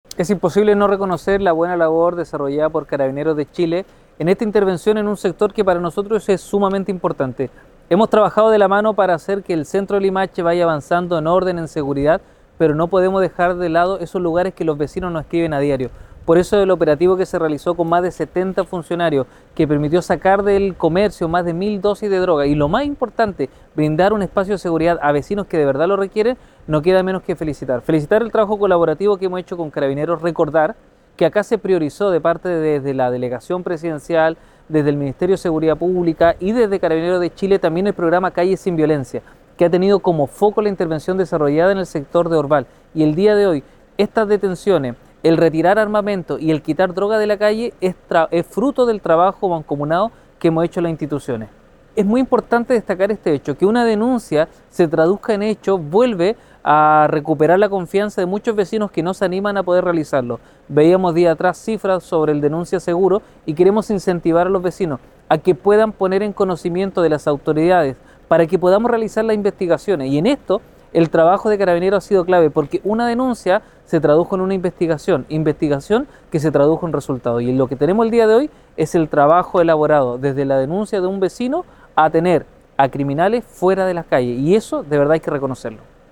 CUNA-ALCALDE-LIMACHE-.mp3